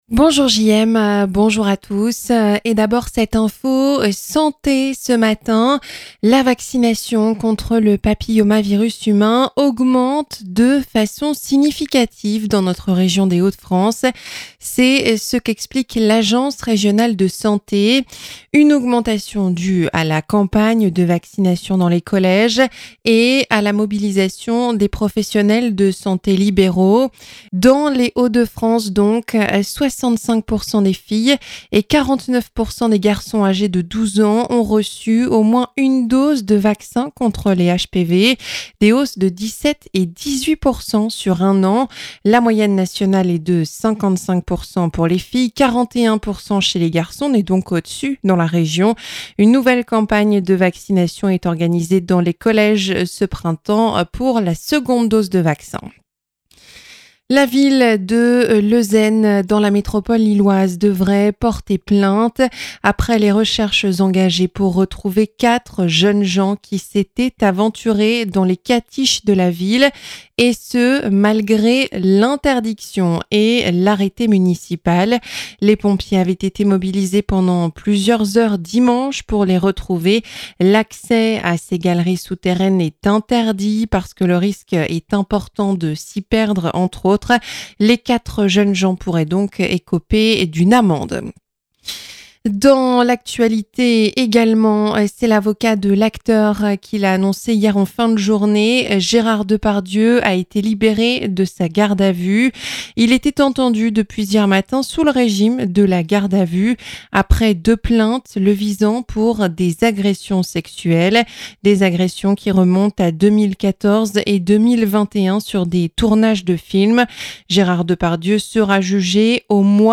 Journal 7h - La vaccination contre le papillomavirus en hausse dans les Hauts de France